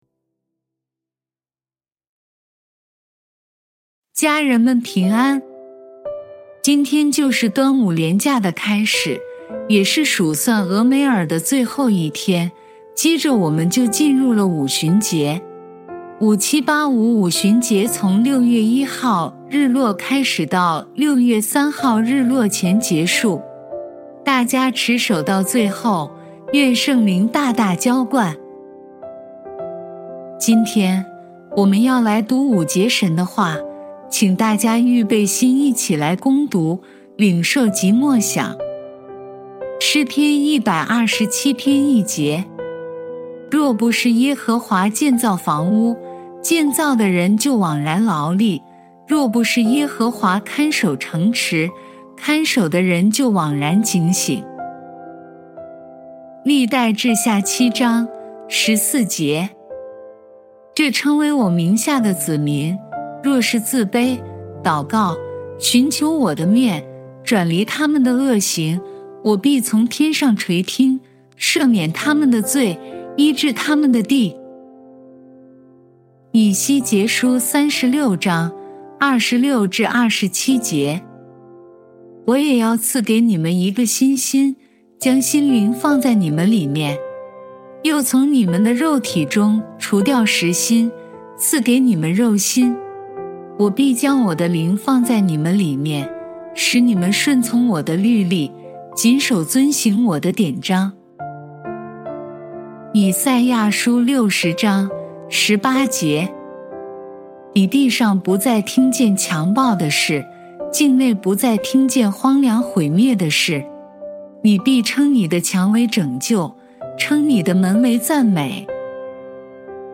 数算俄梅珥第49天祷告